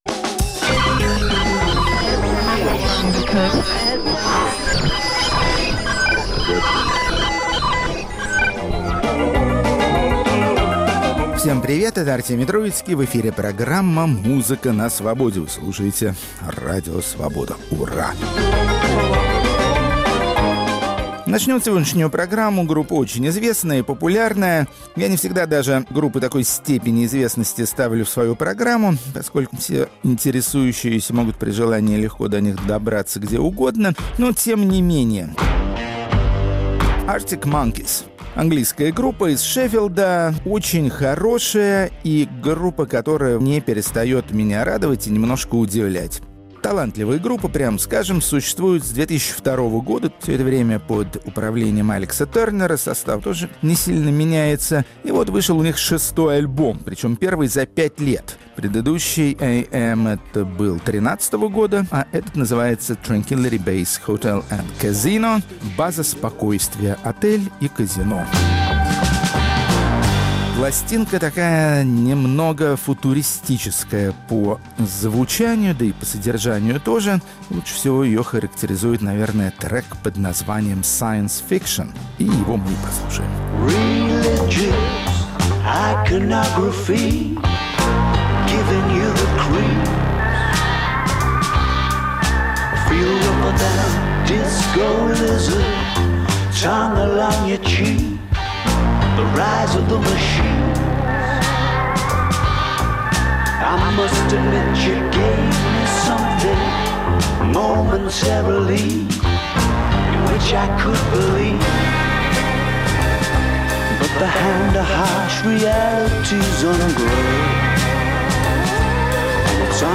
Музыка на Свободе. 16 июня, 2019 Африканские музыканты из пустынных стран, захваченных Сахарой. Рок-критик Артемий Троицкий серьёзно изучил местные напевы и теперь помогает слушателям отличить малийский рок-н-ролл от панка туарегов.